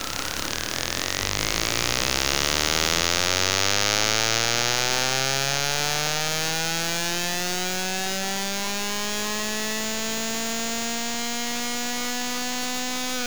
Seat ibiza 1200cm3 entièrement d'origine au niveau moteur et boite (sauf 17° d'avance au lieu de 5°) avec un carbu pierburg 1B3 diam 25mm #-o